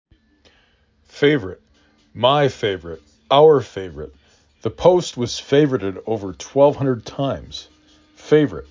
fa vor ite
f A v r ə t